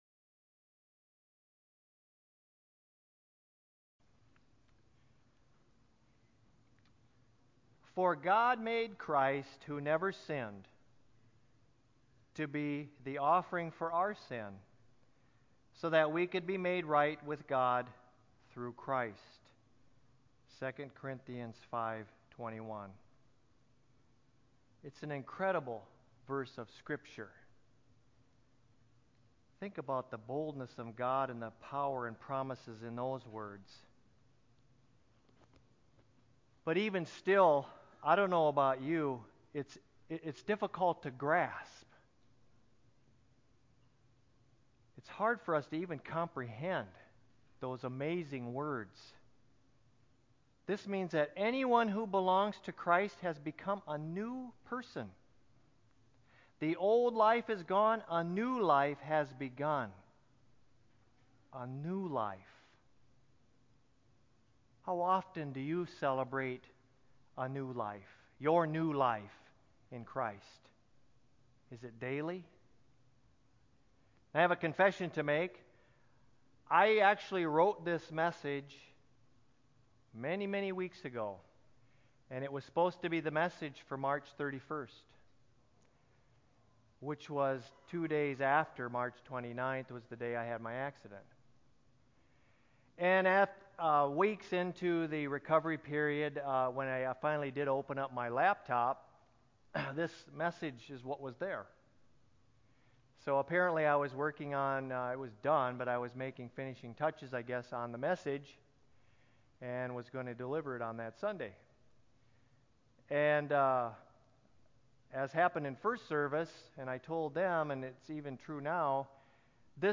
church-sermon7.21.19-CD.mp3